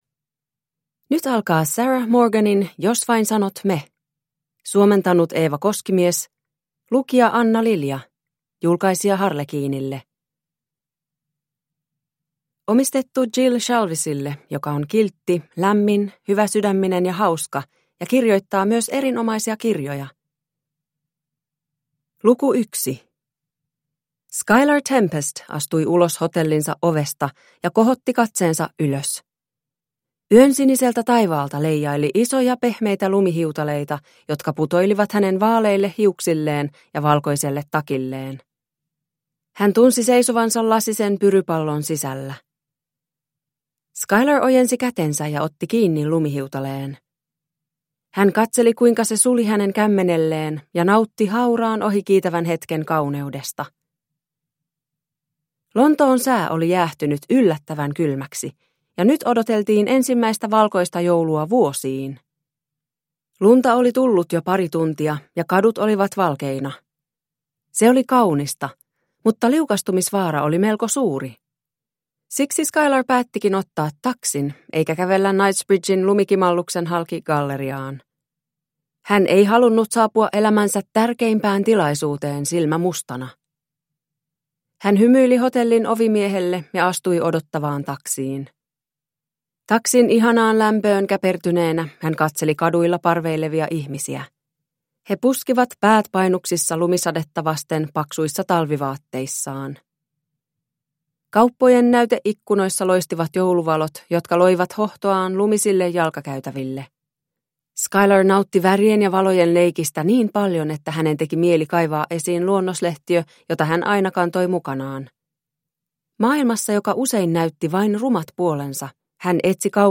Puffin Island – Ljudbok – Laddas ner